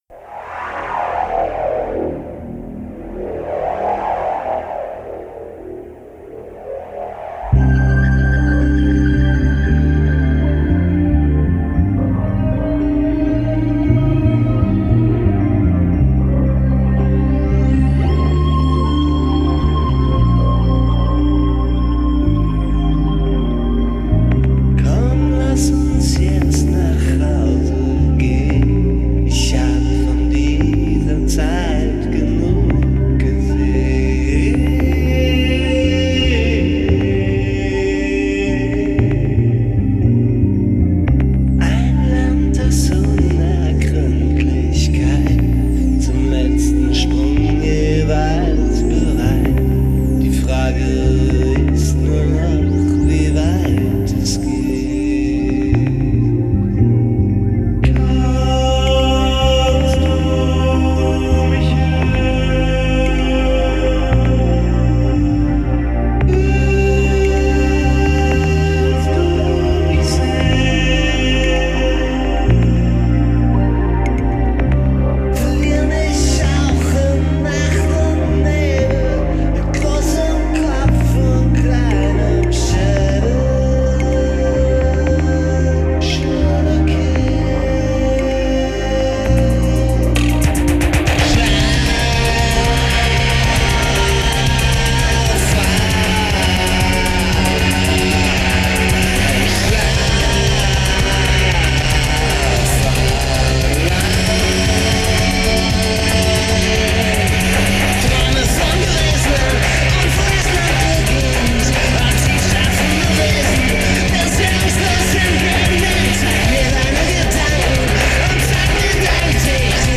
Gesang, Keyboards, Drum-Programming, additional Bass-Programming
Aufgenommen in Thury, Frankreich, 1995.